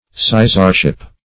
Sizarship \Si"zar*ship\, n. The position or standing of a sizar.